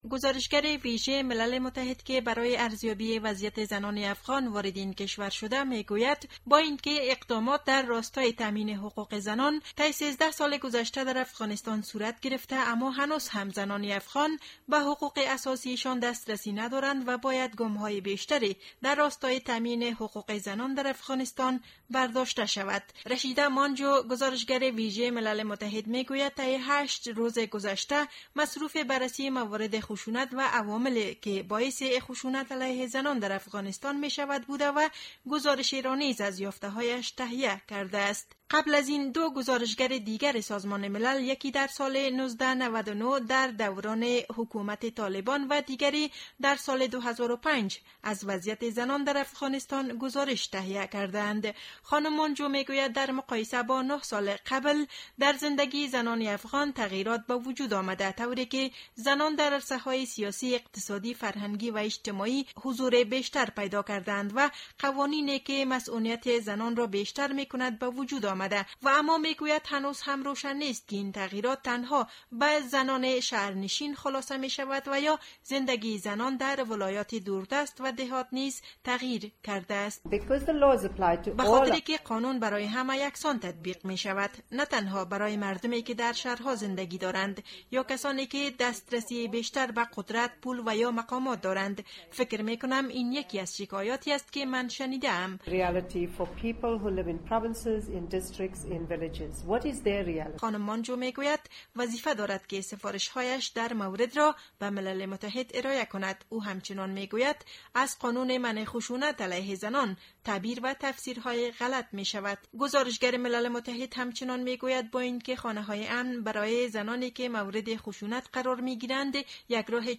گزارش صوتی را در این مورد از پیوند زیر بشنوید: